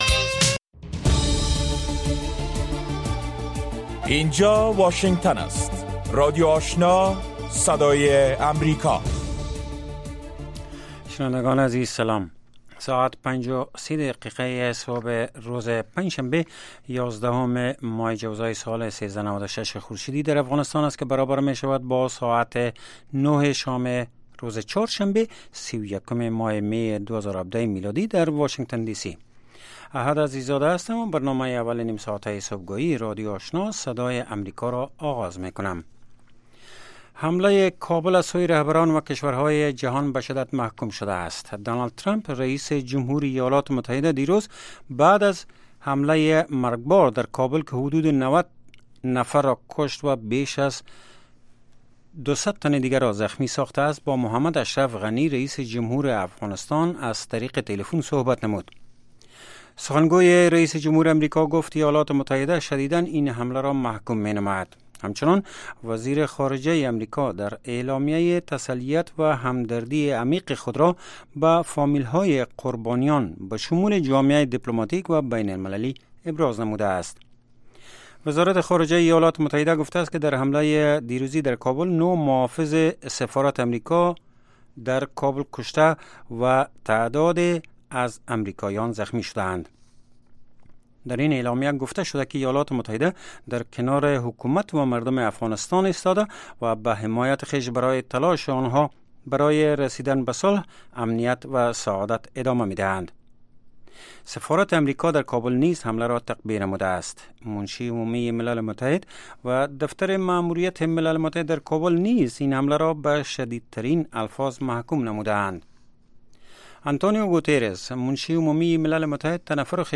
نخستین برنامه خبری صبح